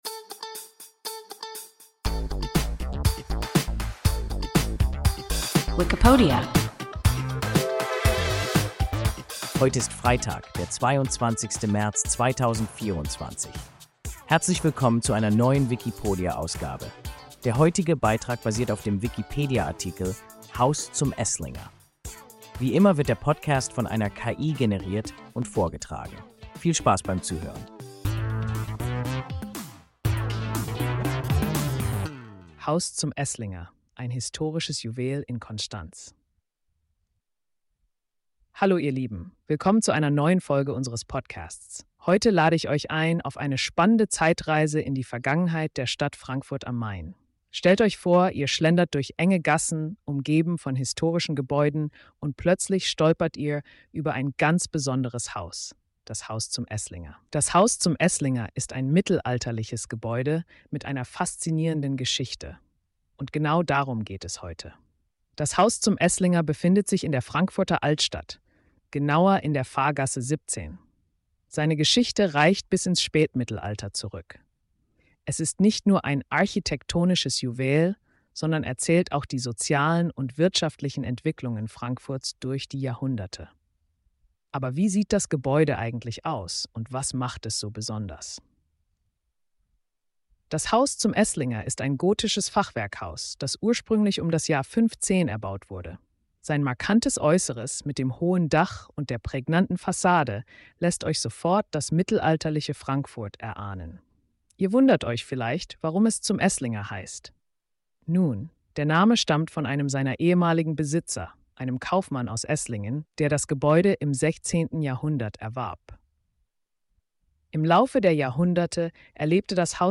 Haus zum Esslinger – WIKIPODIA – ein KI Podcast